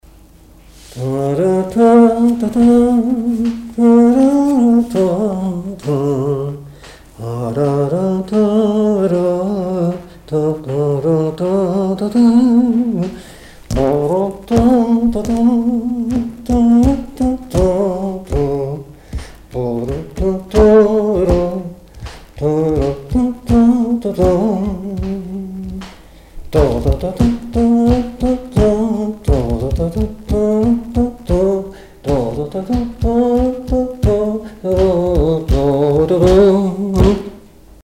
Boupère (Le)
circonstance : fiançaille, noce
Genre brève
Pièce musicale inédite